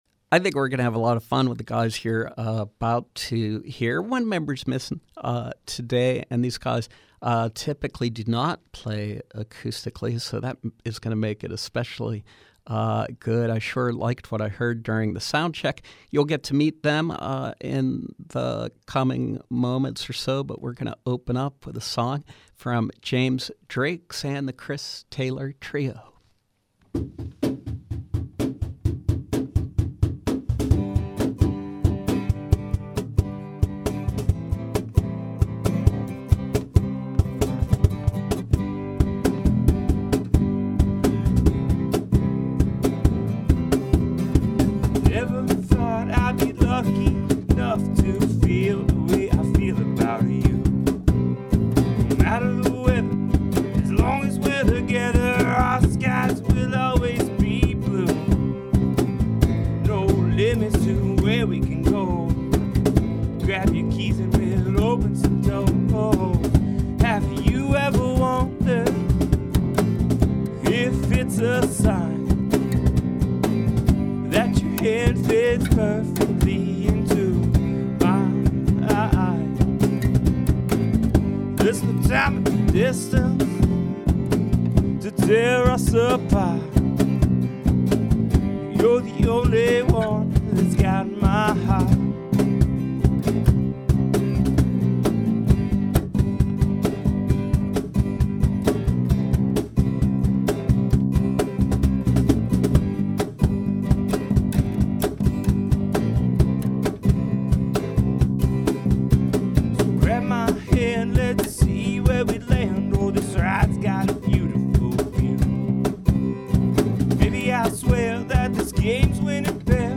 A live acoustic set